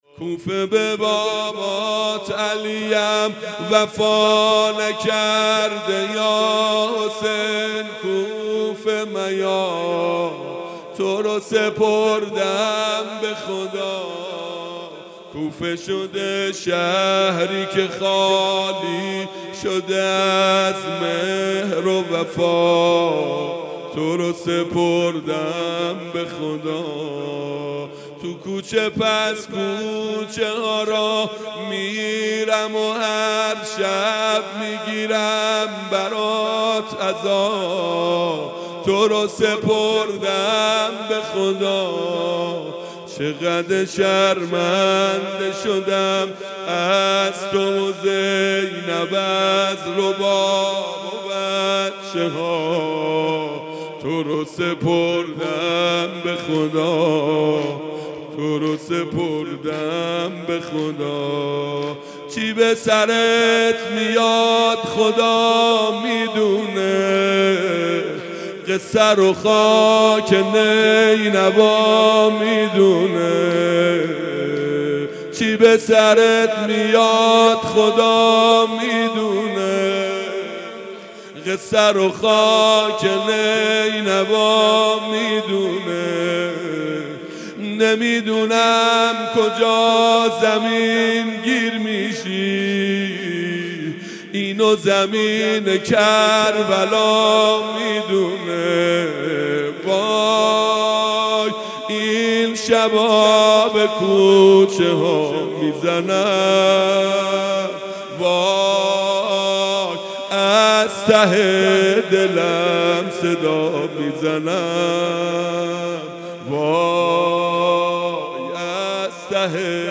محرم 97